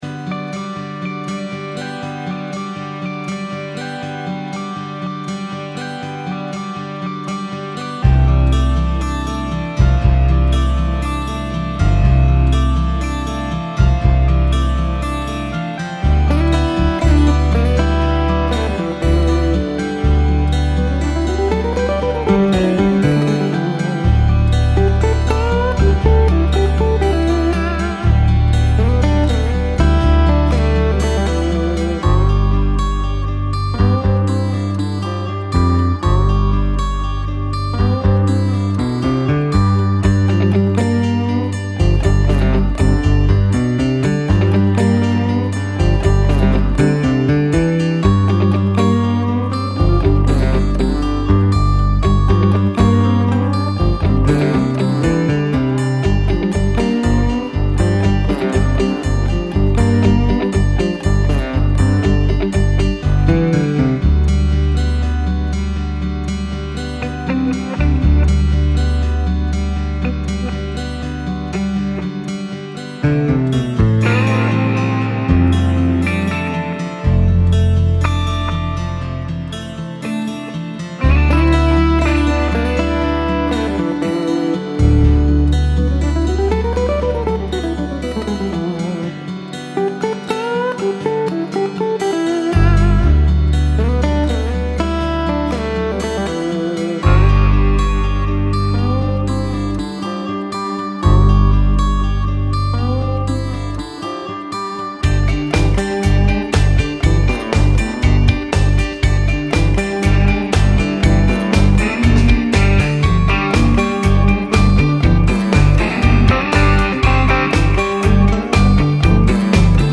Largo [40-50] melancolie - guitare classique - - -